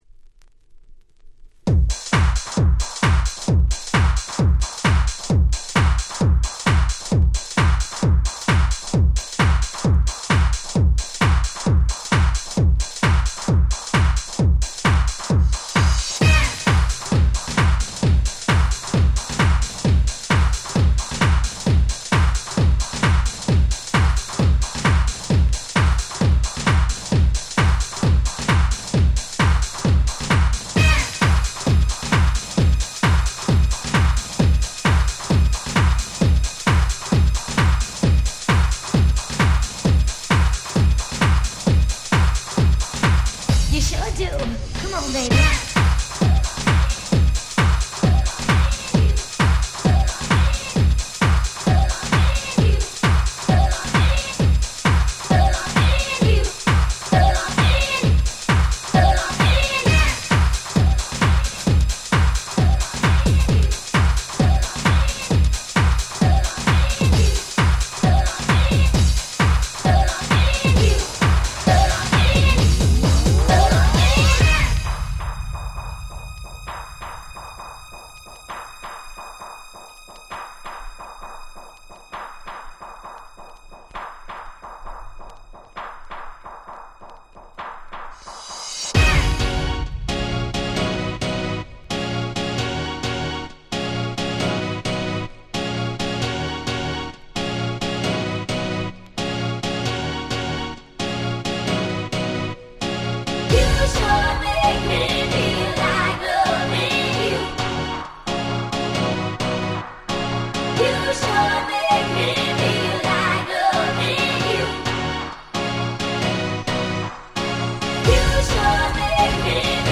94' Super Hit Euro Dance / Vocal House !!
Dance Pop ダンスポップ 90's ハウス